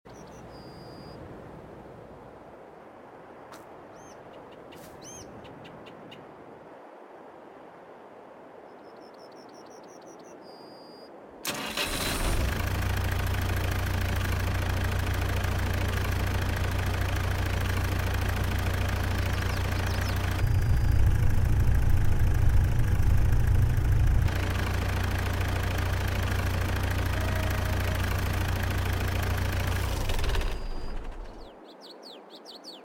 МТЗ 82.1 Нові звуки: sound effects free download